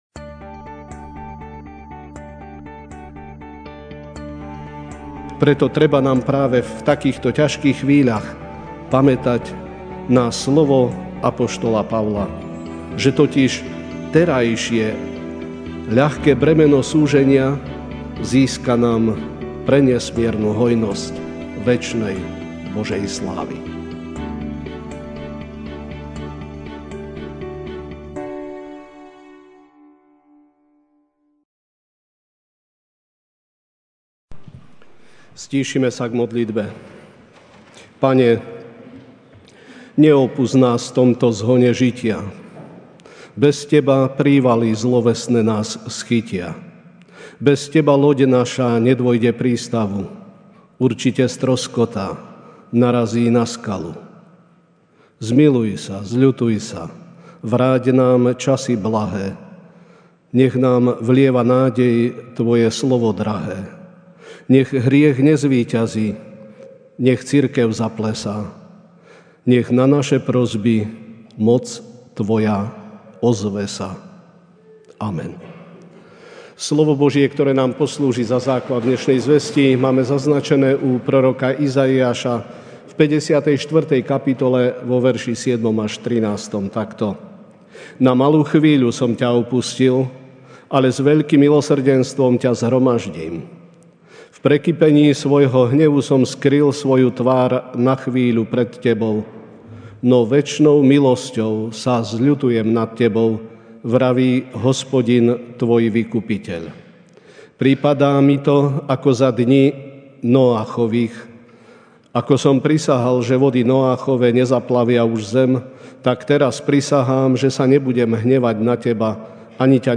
MP3 SUBSCRIBE on iTunes(Podcast) Notes Sermons in this Series Ranná kázeň: Rogate – Modlite sa!